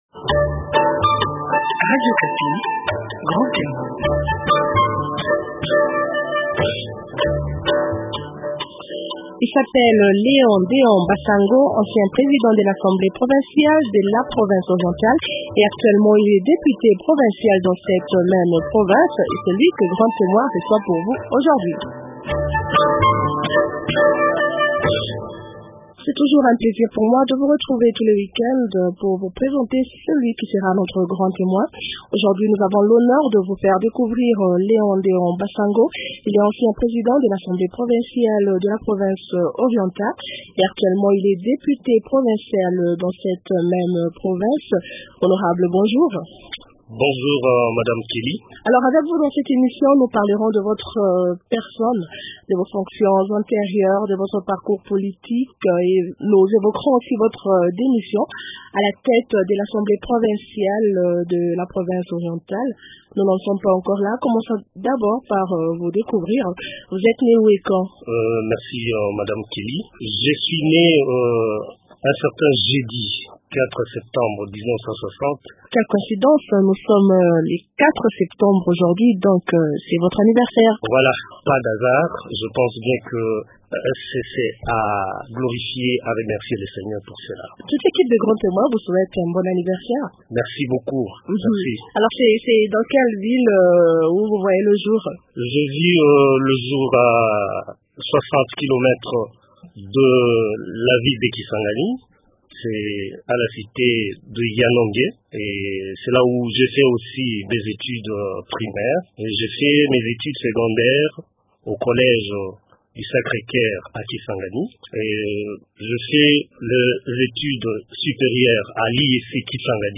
L’invité de grand témoin aujourd’hui est Léon Déon Basango. Il est ancien président de l’assemblée provinciale de la Province Orientale, et actuellement, il est député provincial dans cette même province.